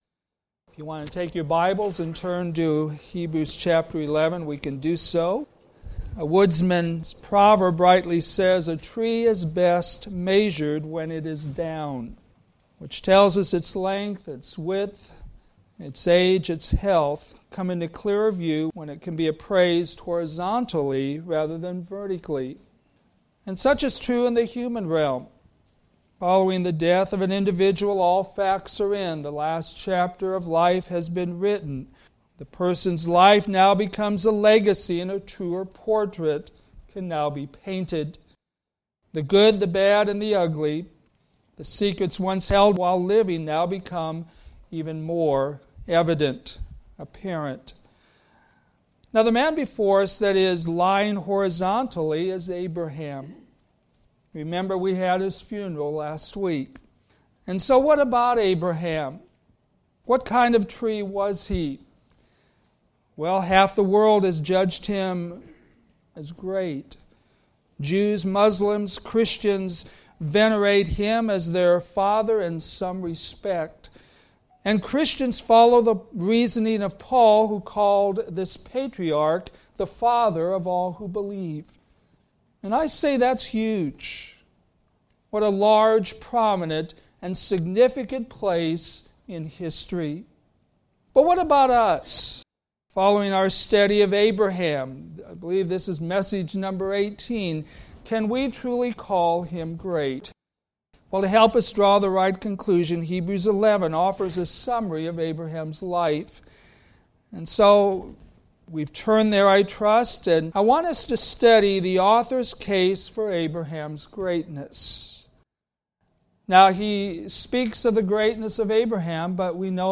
All sermons available in mp3 format